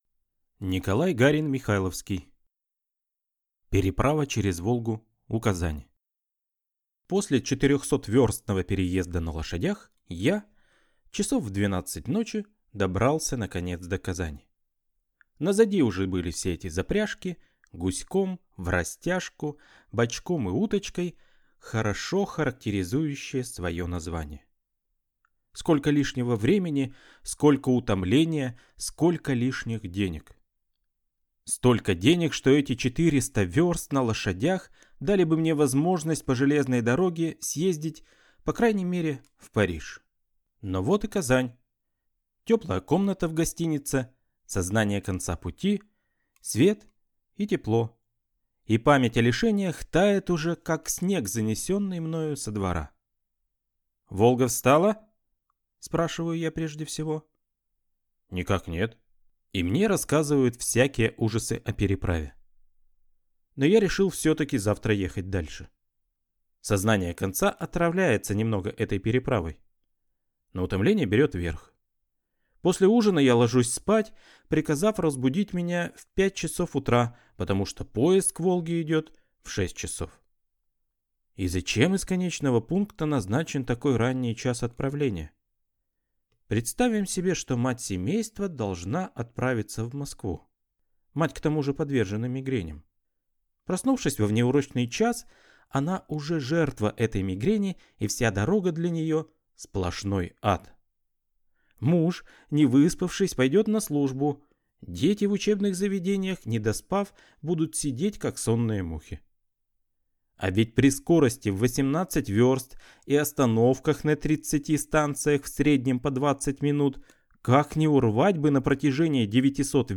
Аудиокнига Переправа через Волгу у Казани | Библиотека аудиокниг